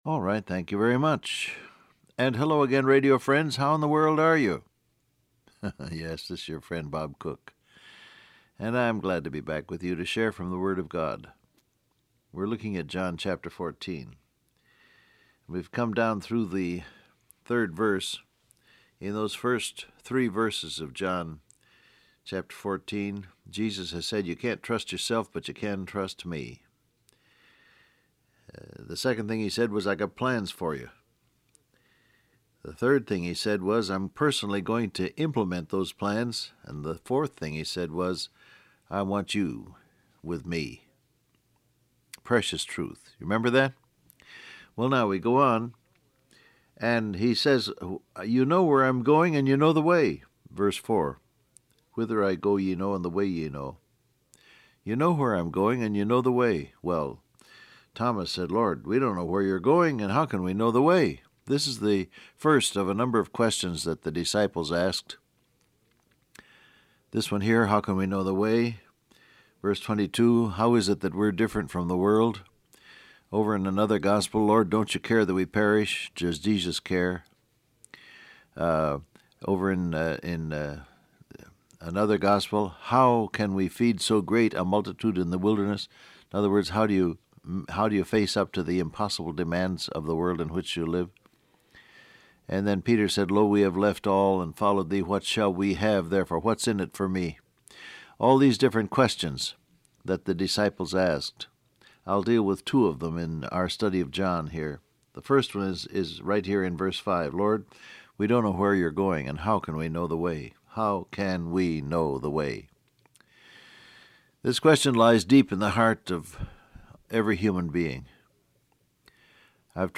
Download Audio Print Broadcast #6915 Scripture: John 14:3-4 Topics: Faith , Hunger For God , Devils Ploy , Way To Know God Transcript Facebook Twitter WhatsApp Alright, thank you very much.